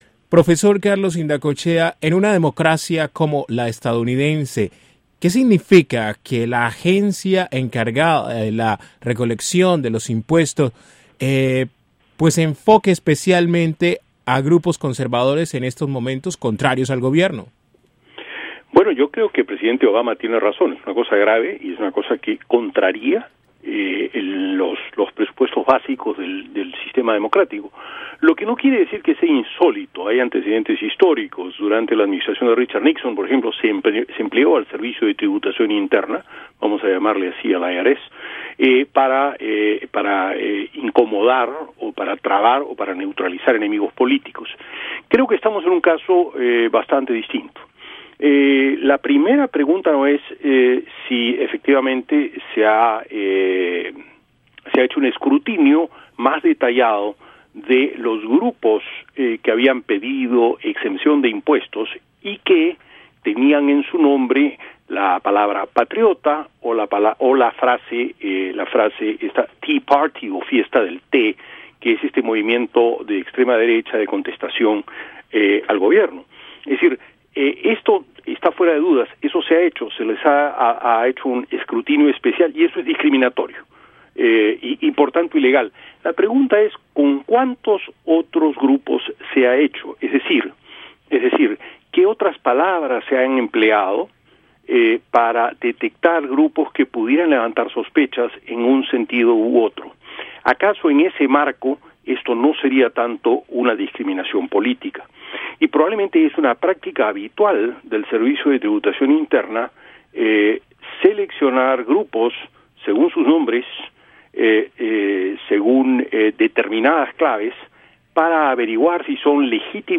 Profesor explica escándalo IRS